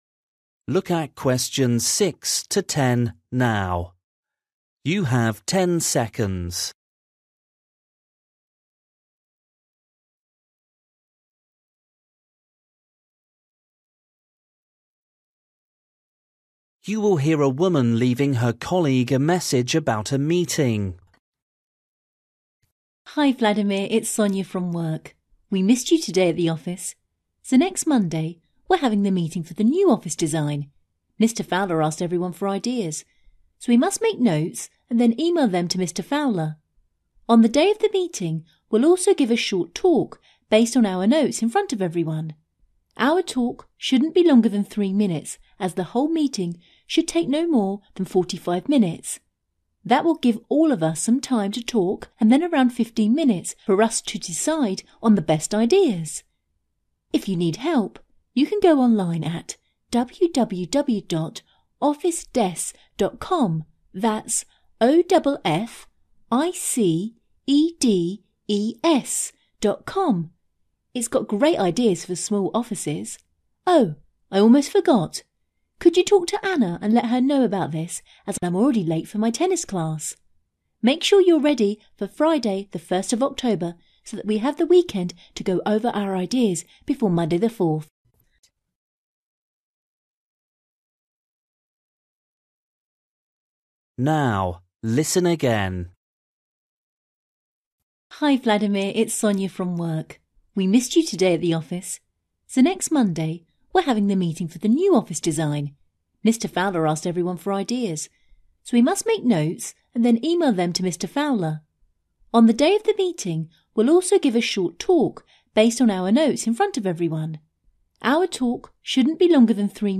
You will hear a woman leaving her colleague a message about a meeting.